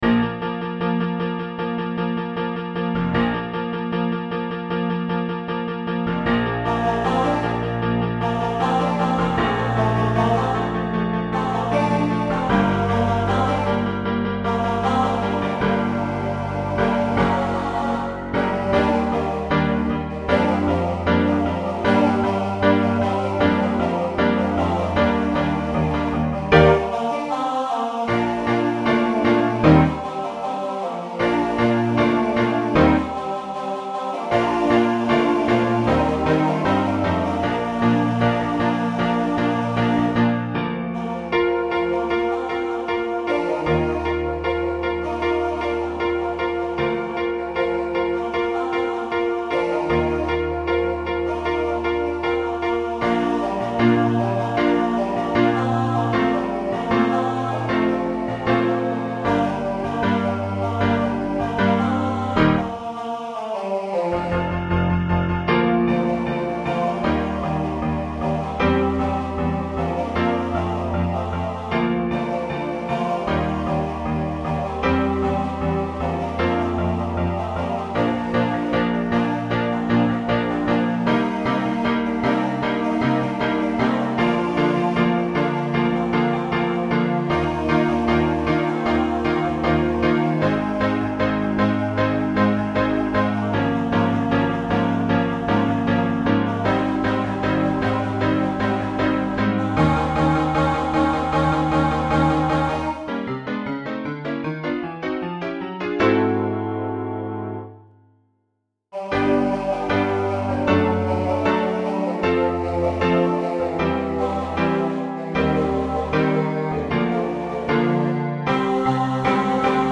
guys/girls feature
Voicing Mixed Instrumental combo Genre Broadway/Film , Rock